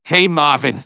synthetic-wakewords
ovos-tts-plugin-deepponies_Medic_en.wav